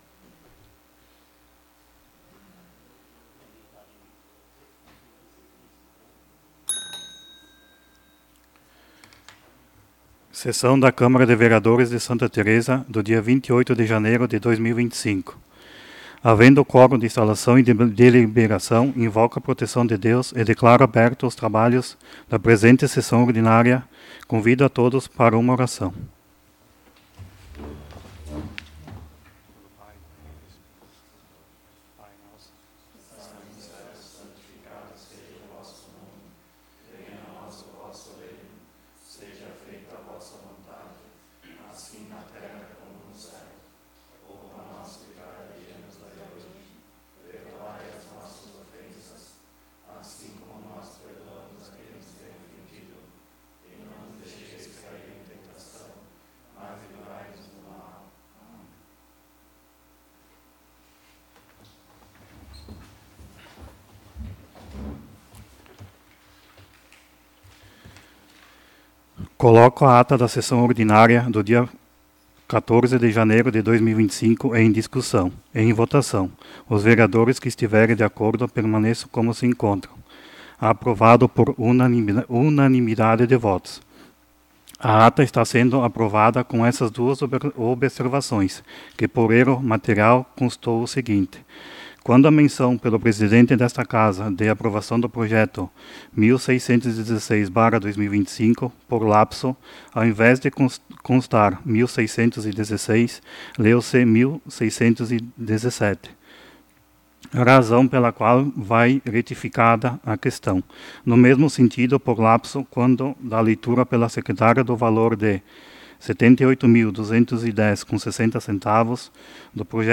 Câmara Municipal de Santa Tereza/RS
02° Sessão Ordinária de 2025